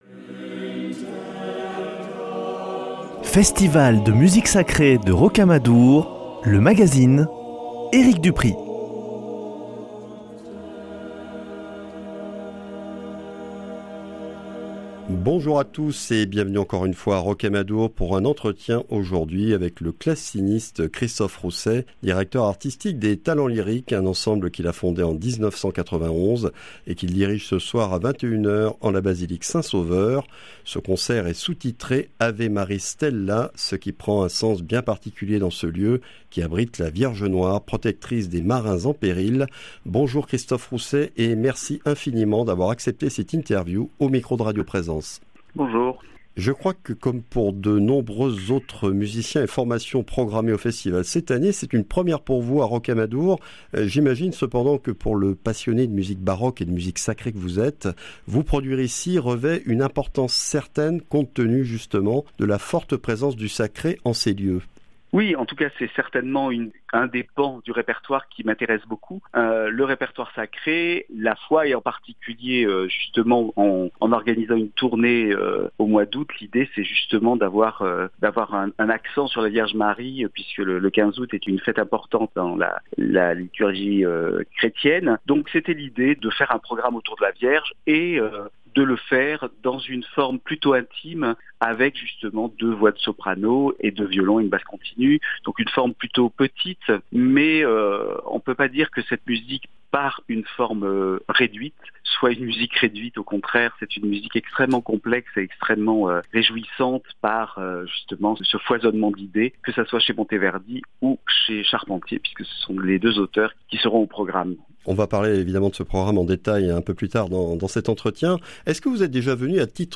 Festival de Rocamadour 2023, ITW du 23 août : Christophe Rousset